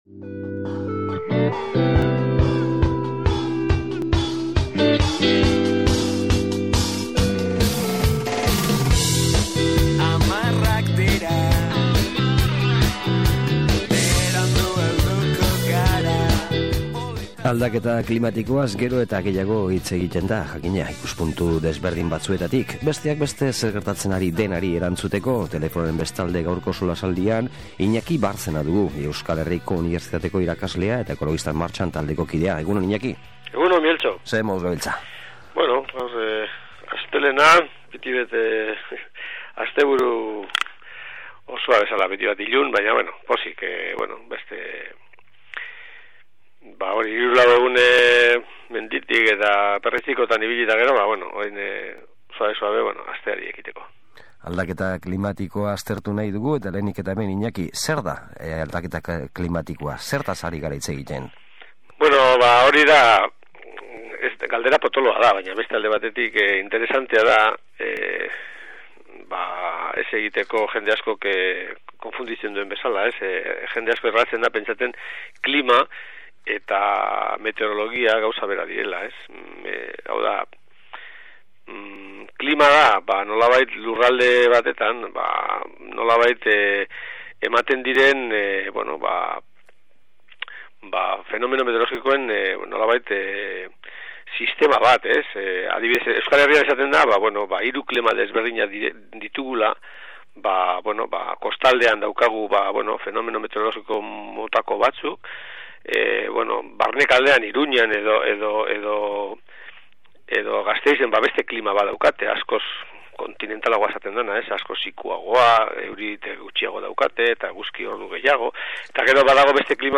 SOLASALDIA: Aldaketa klimatikoa eta alternatibak